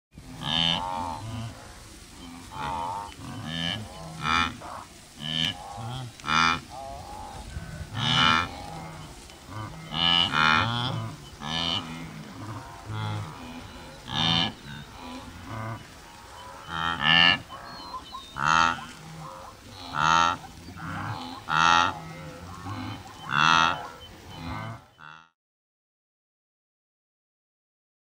5. True or False: Only cows make mooing sounds.
False! Wildebeest moo, too!
These creatures have a wide array of noisy vocalizations, from basic moos to explosive snorts.
Wildebeest-Sounds-in-the-Serengeti.mp3